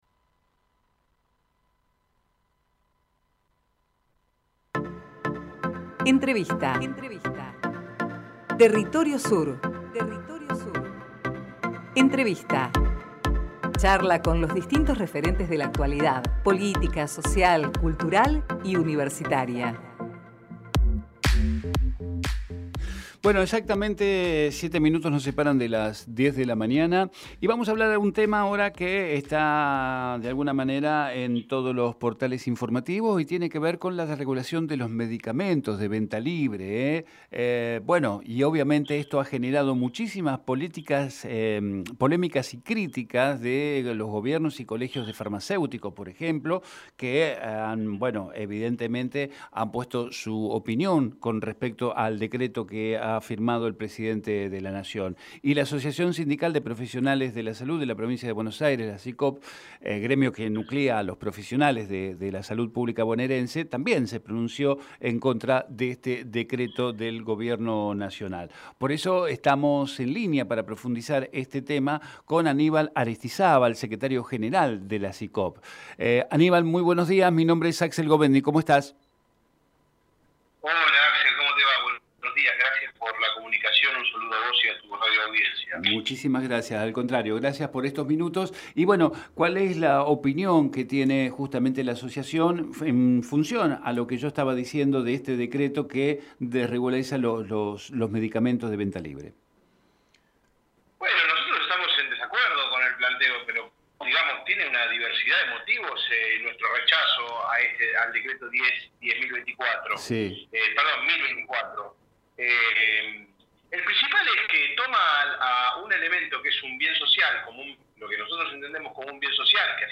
Compartimos con ustedes la entrevista realizada en Territorio Sur